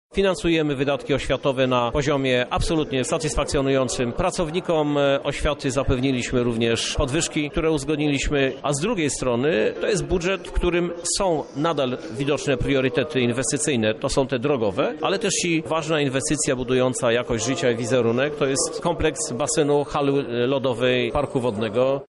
O najważniejszych punktach budżetu mówi prezydent miasta, Krzysztof Żuk